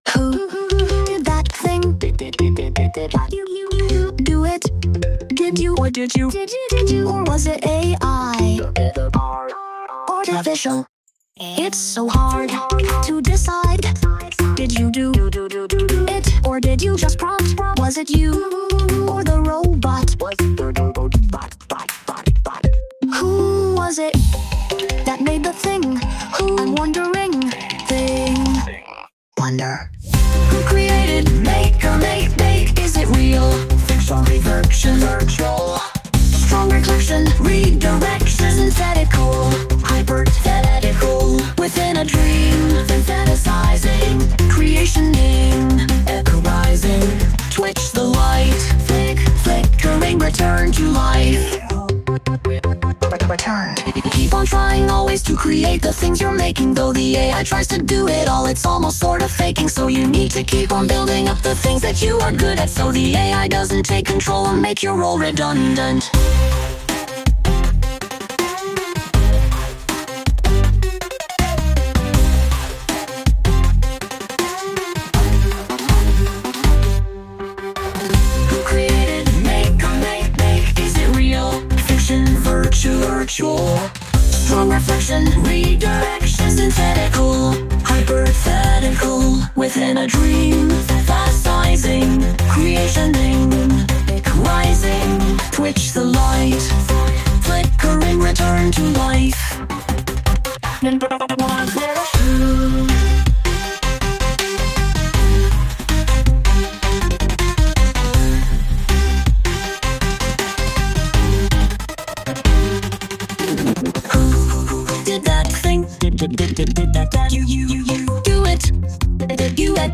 Daily music, daily picture, daily sitcom scene. All somewhat mostly A.I. generated.
Sung by Suno